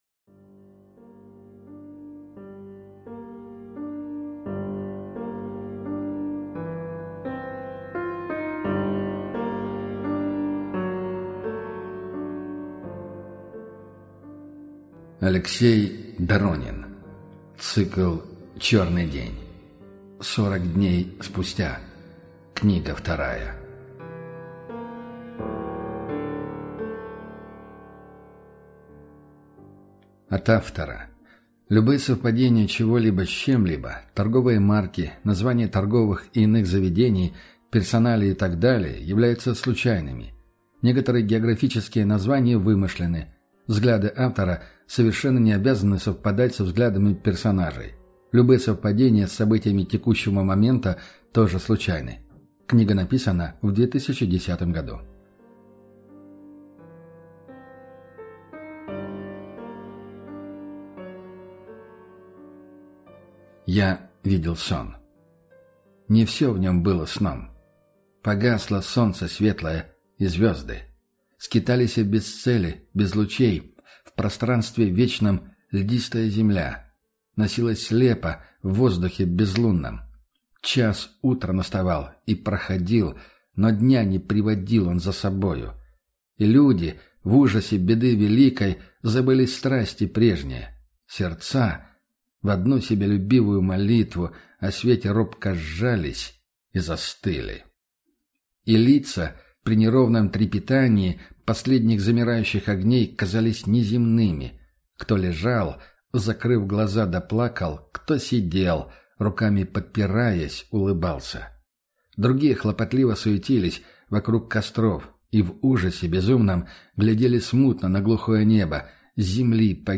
Аудиокнига Сорок дней спустя | Библиотека аудиокниг
Прослушать и бесплатно скачать фрагмент аудиокниги